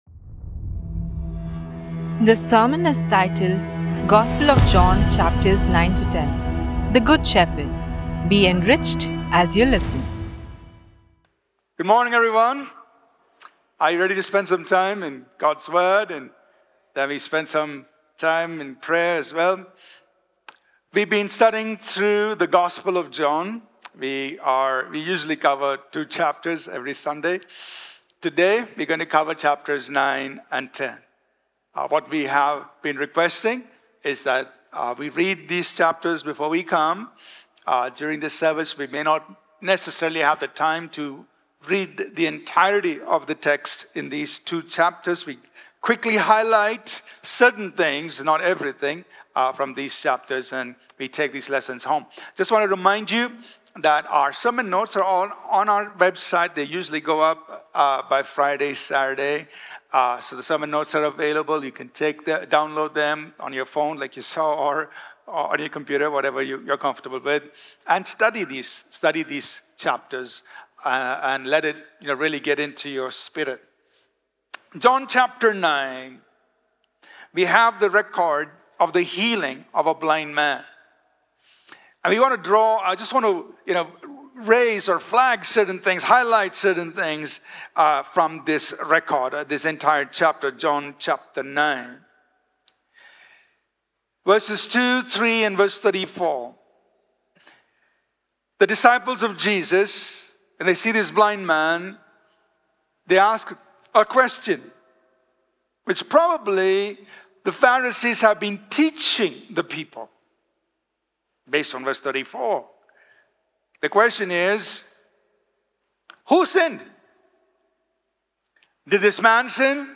This sermon includes free audio (mp3) and video of this powerful, motivational, expository teaching, free printable PDF sermon outlines, sermon notes and small group study guide with discussion questions and presentation slides.